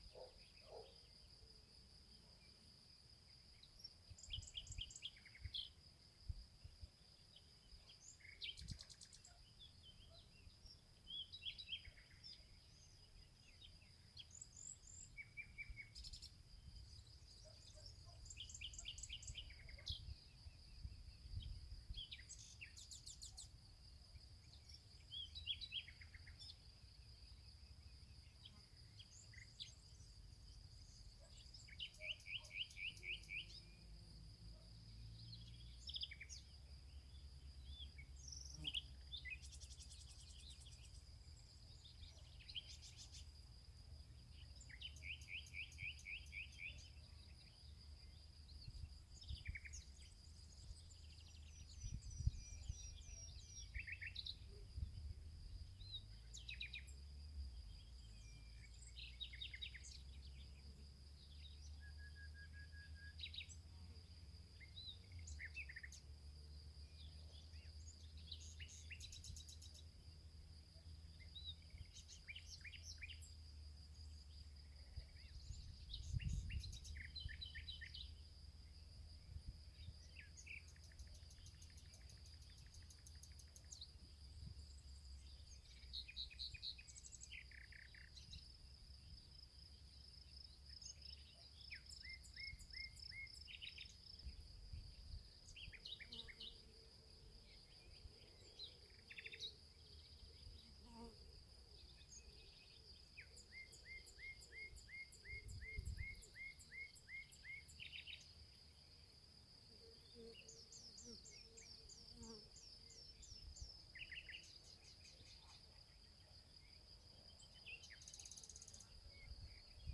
Ранние пташки в поле